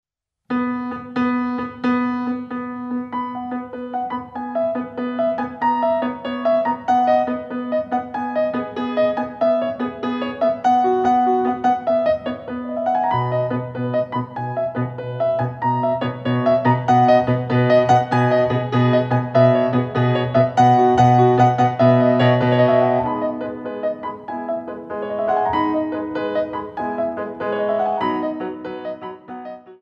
Petit Allegro